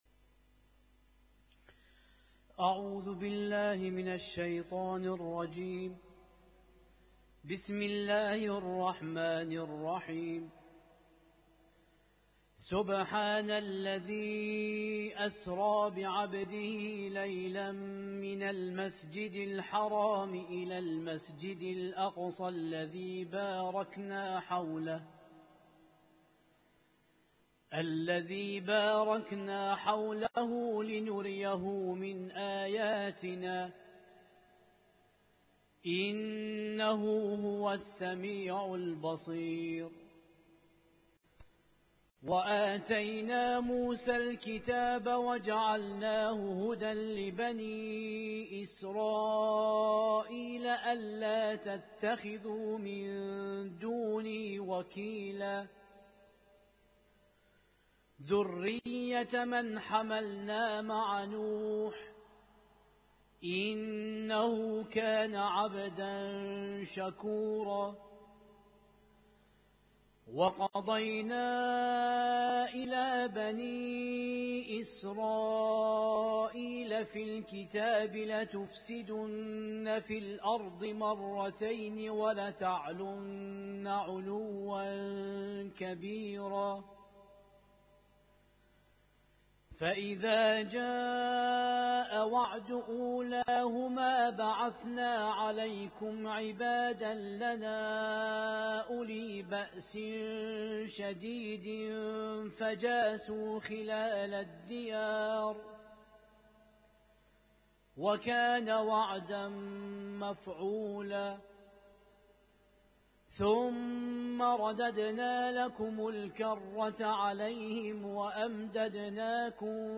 Husainyt Alnoor Rumaithiya Kuwait
القرآن الكريم